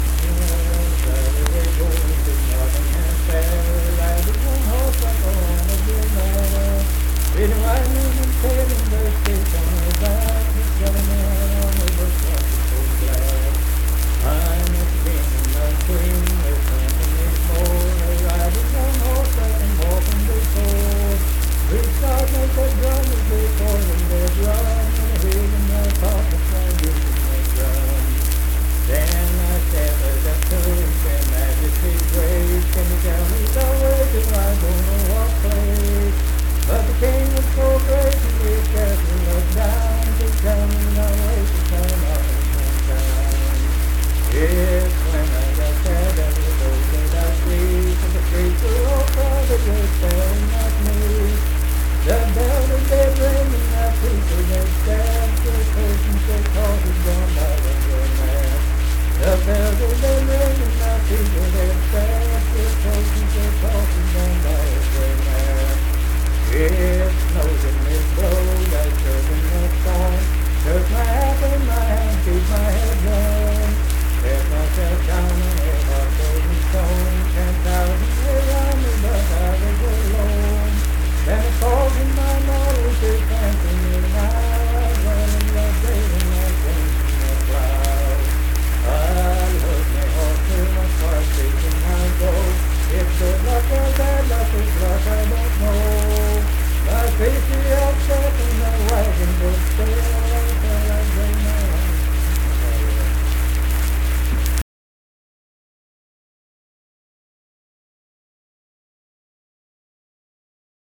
Unaccompanied vocal music
Performed in Kanawha Head, Upshur County, WV.
Humor and Nonsense, Children's Songs
Voice (sung)